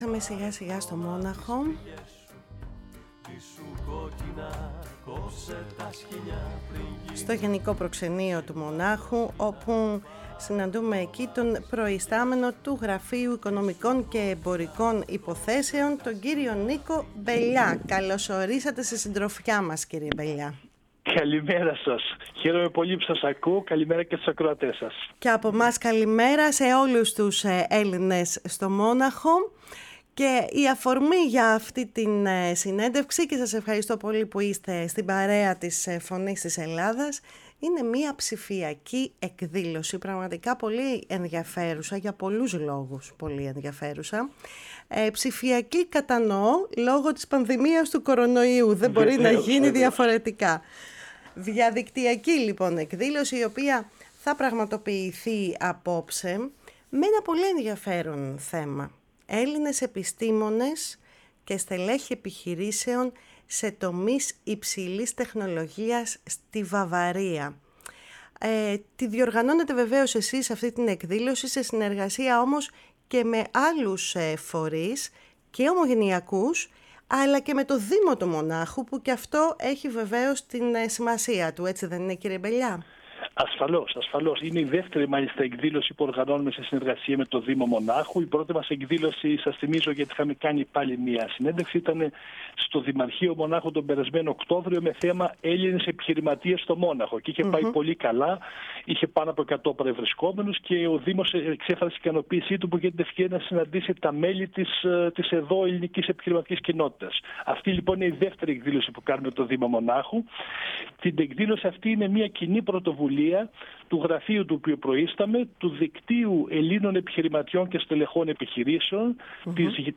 μίλησε στη “Φωνή της Ελλάδας” και συγκεκριμένα στην εκπομπή “Κουβέντες μακρινές”